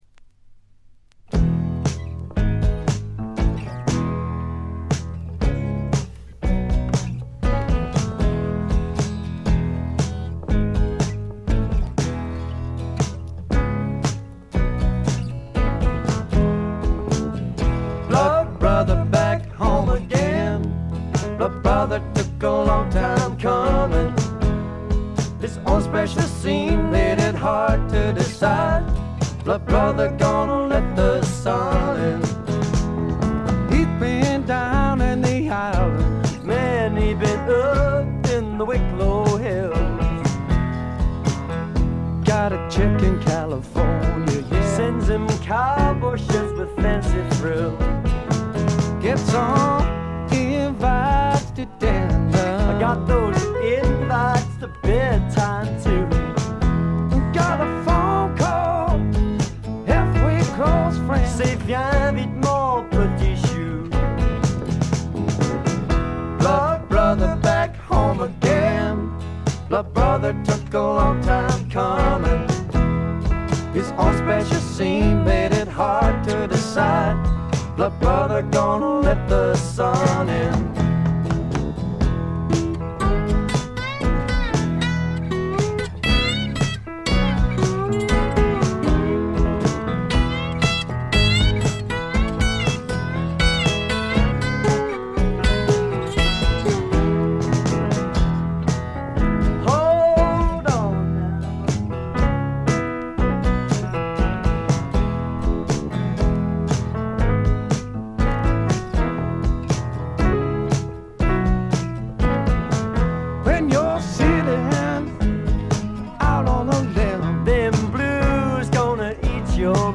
ほとんどノイズ感無し。
聴くたびにご機嫌なロックンロールに身をゆだねる幸せをつくずく感じてしまいますね。
試聴曲は現品からの取り込み音源です。